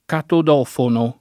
[ katod 0 fono ]